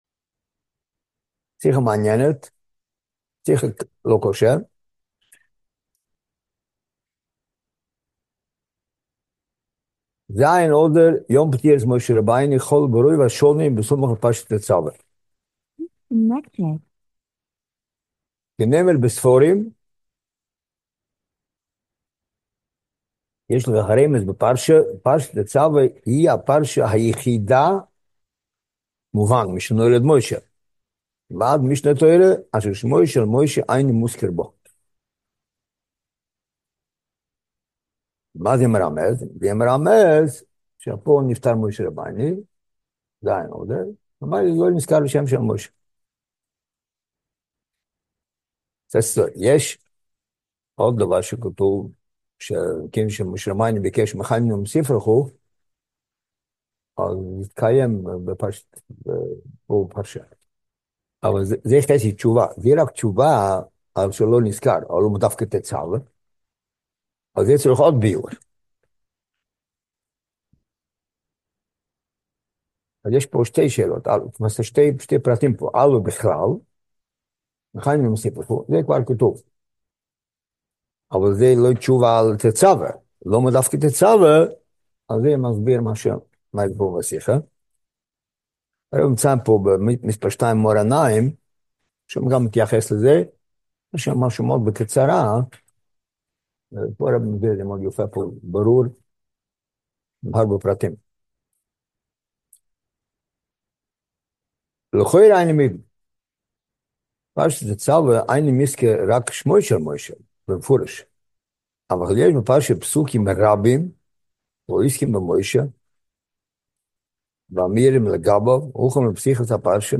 שיעור בעיון עברית